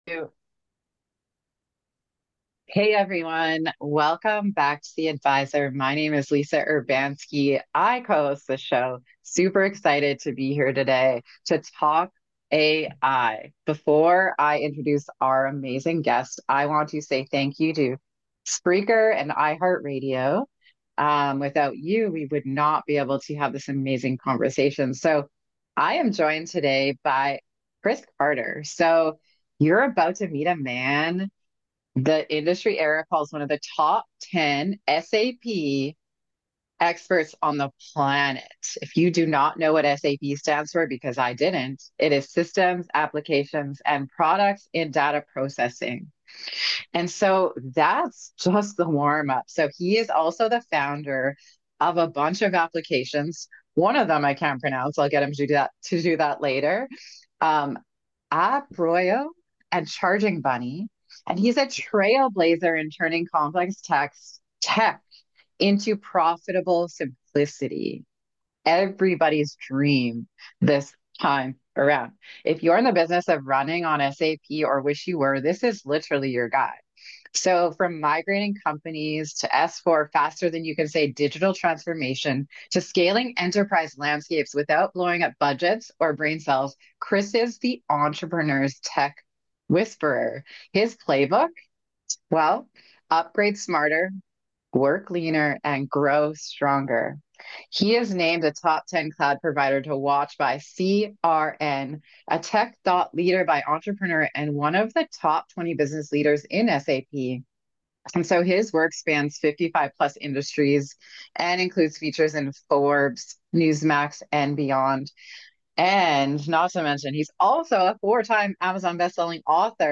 a captivating conversation